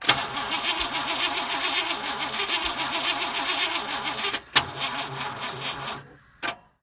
Звуки стартера автомобиля
Автомобиль отказывается заводиться